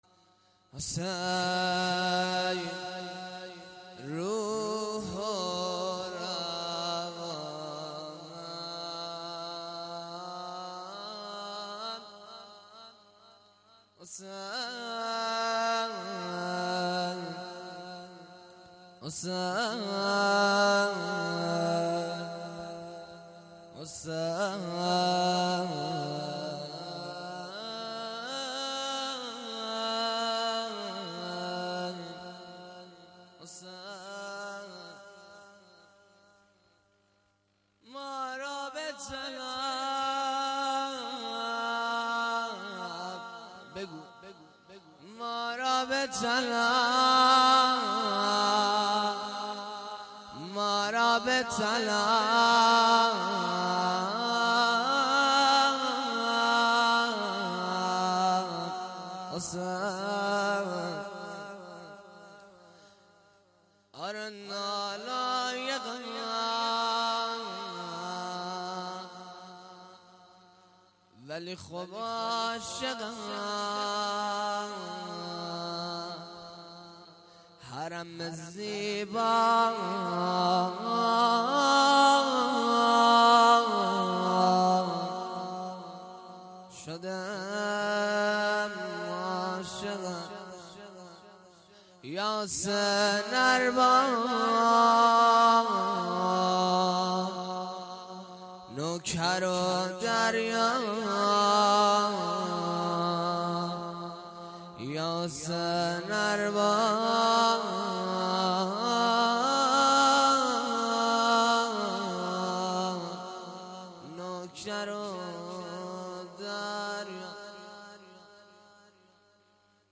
• روضه اجراشده در محفل زینبیون مشهدالرضا